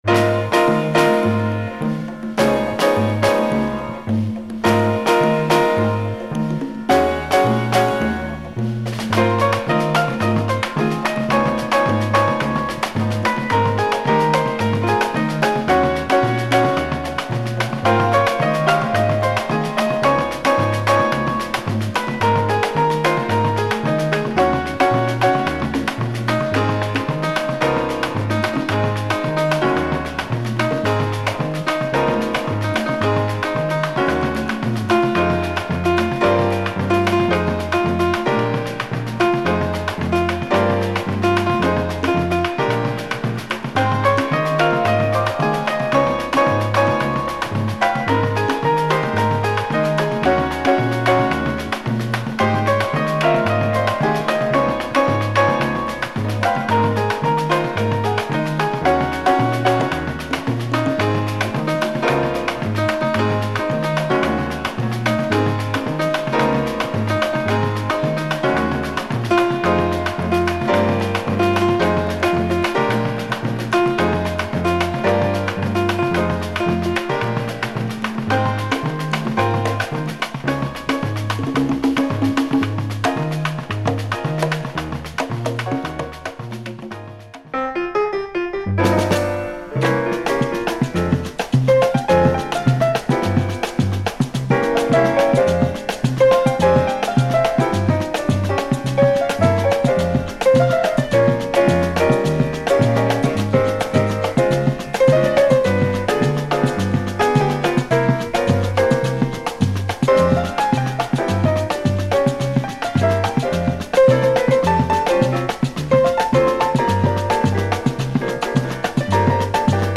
Superb biguine jazz album
Some dust.